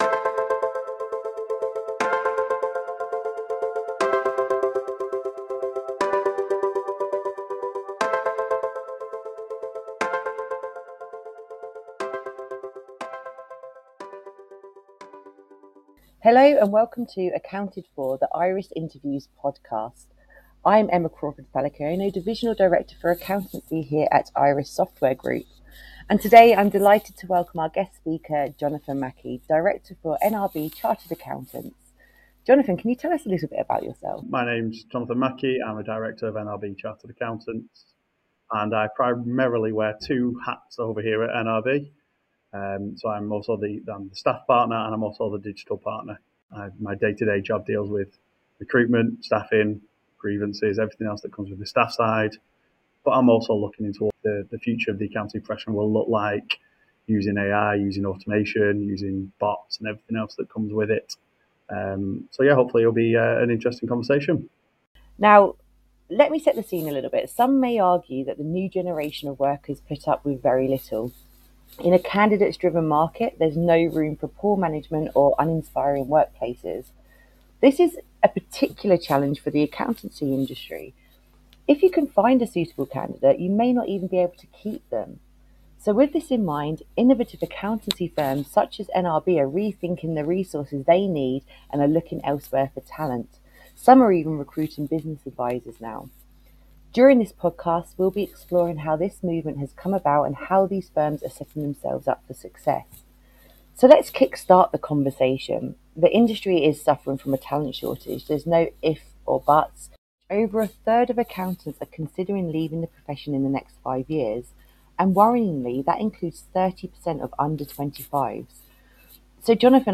IRIS Interviews